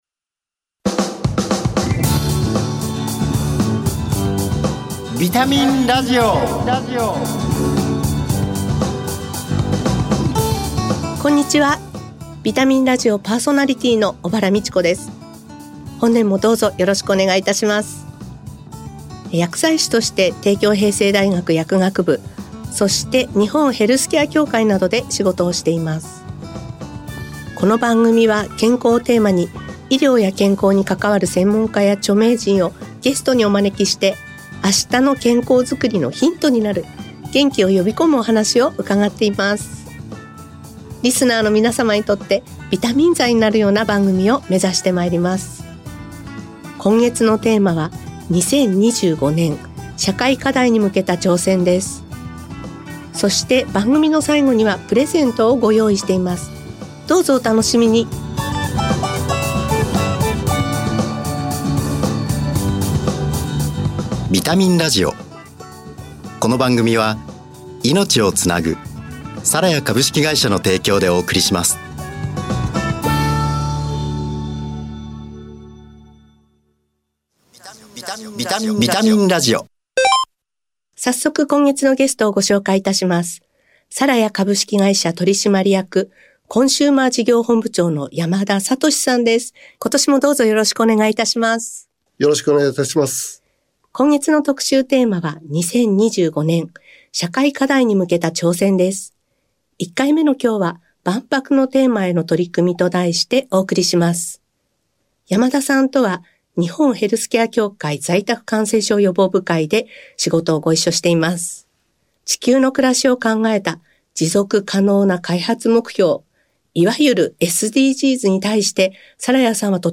「健康」をテーマに、元気になる情報をお届けします。医療従事者など専門家がゲストに登場。